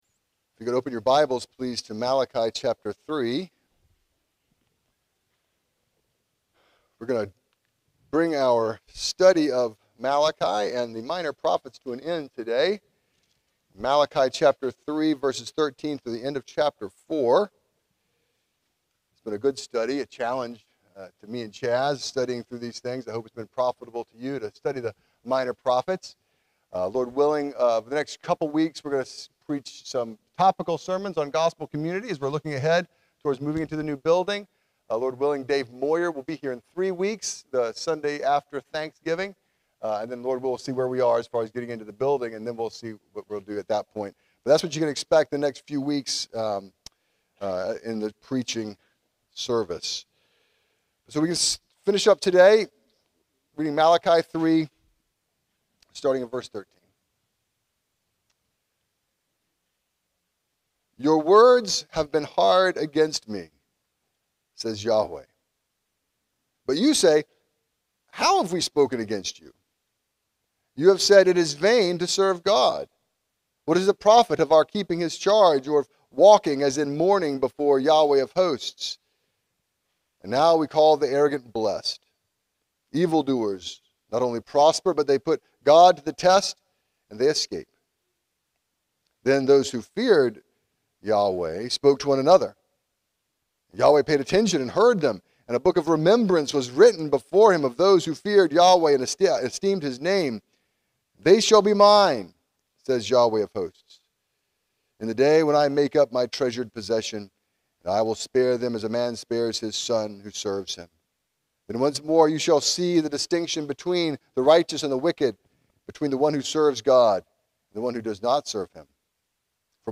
Sermons – Redeemer Community Church